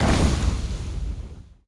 Media:ElPrimo_super_flame.wav 技能音效 super 飞身肘击火焰音效
ElPrimo_super_flame.wav